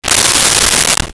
Close Shower Curtain 02
Close Shower Curtain 02.wav